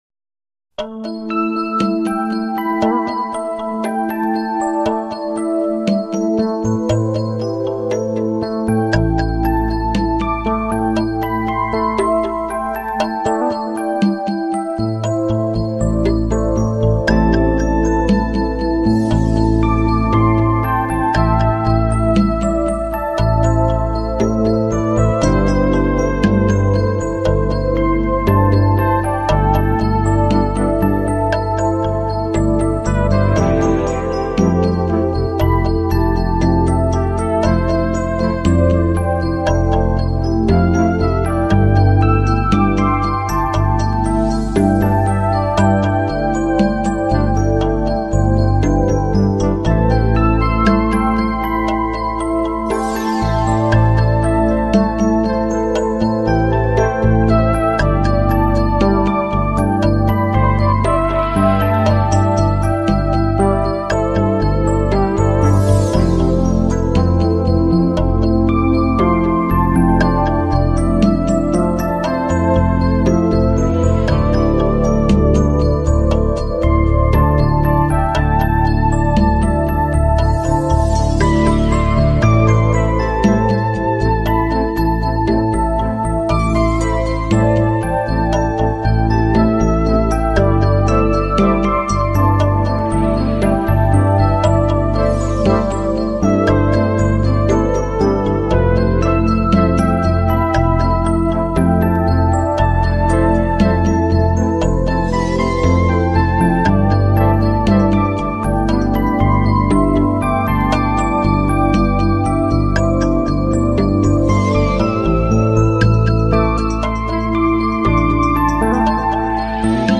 优美纯音乐专辑
专辑歌手：纯音乐
格也作了调整：顺应回归自然的潮流在原曲中加入大自然音效为背景，并且以
出类拔萃，音色的清晰秀丽，细致柔顺。